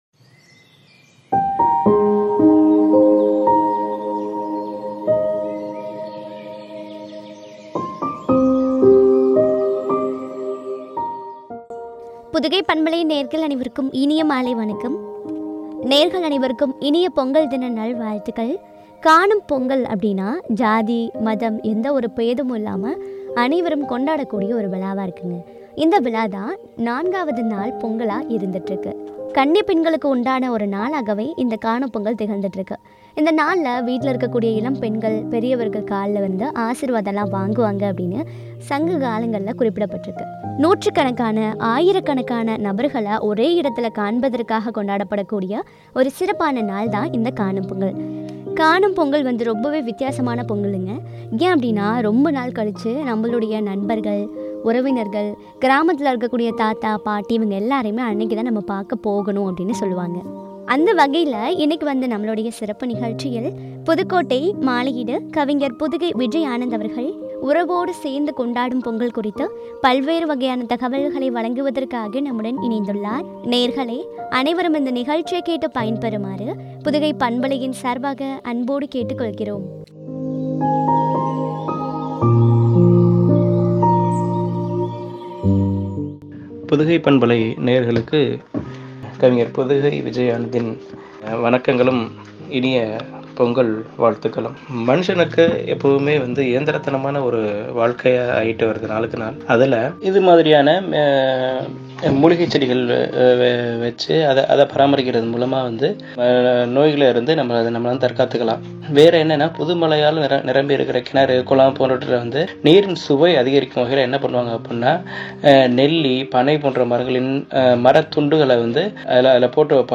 “உறவுகள் சேரும் பண்டிகை” குறித்து வழங்கிய உரையாடல்.